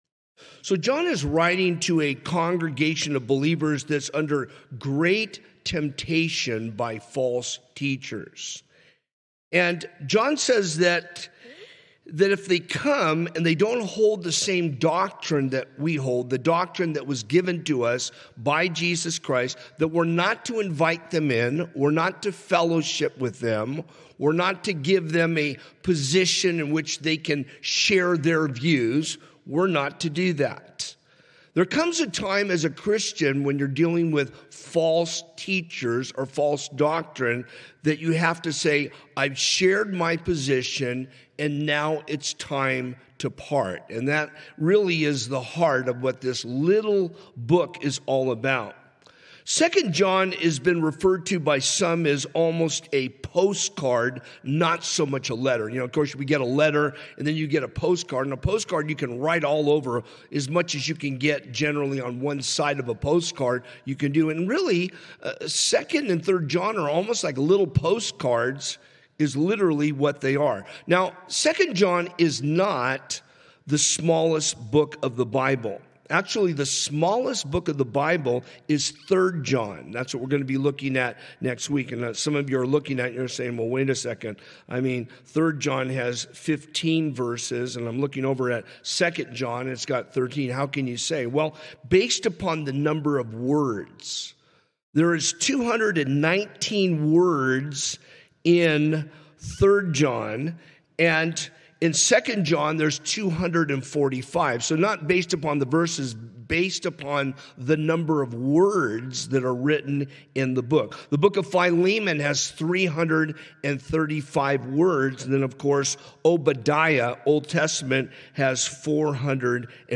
Calvary Chapel Rialto – Sermons and Notes